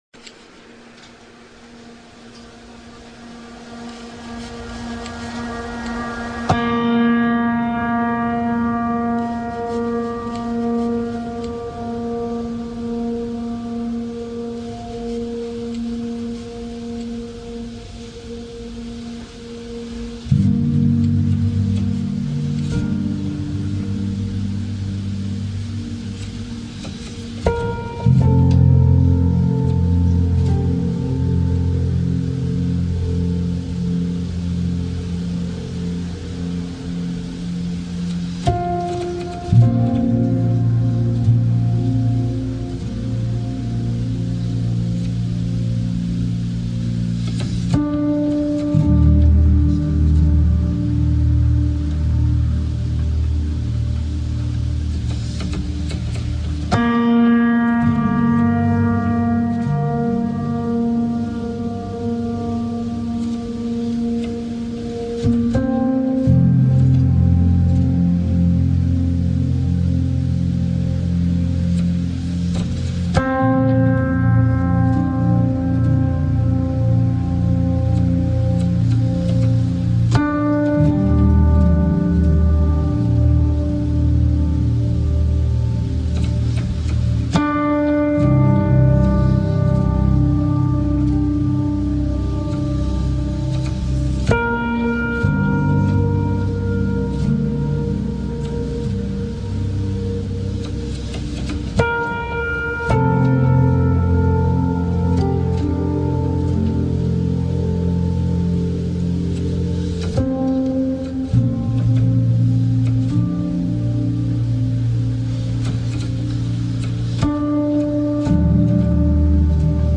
Genre: Top 40.